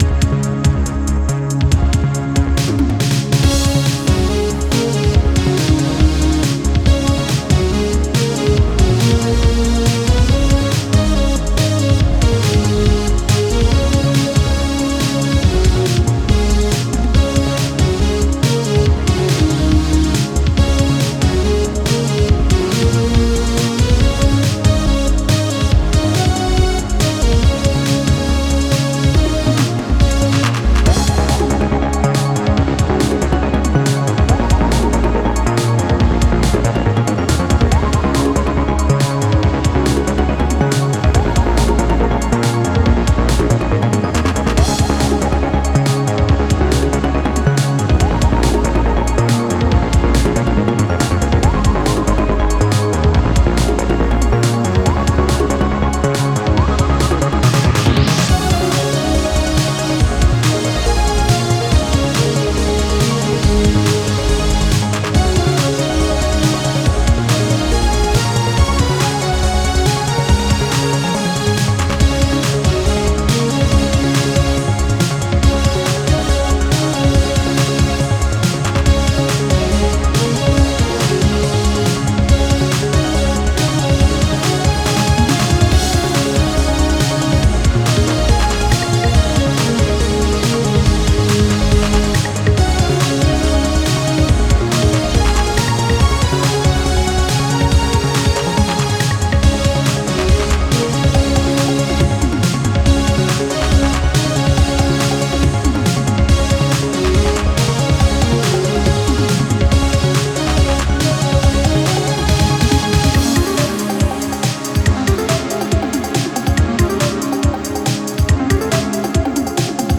Synthwave music for field theme.